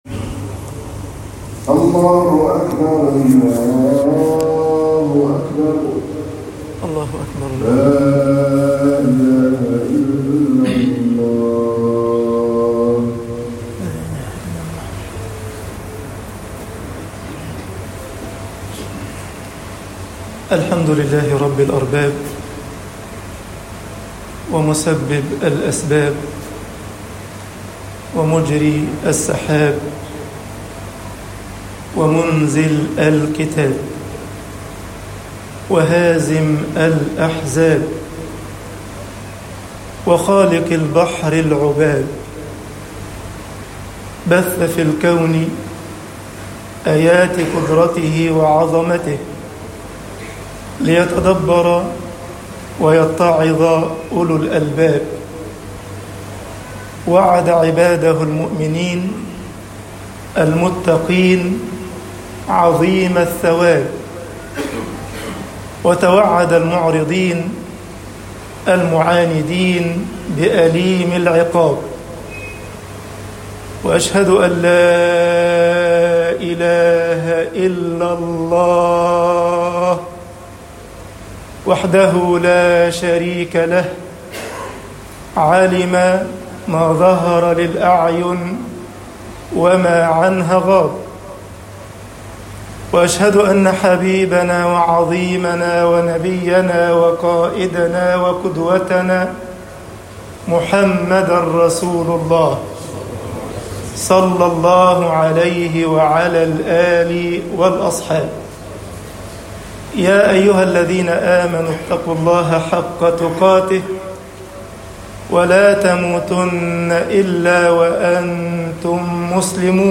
خطب الجمعة - مصر آيَةُ الْمِحْنَة وَالاخْتِبَار طباعة البريد الإلكتروني التفاصيل كتب بواسطة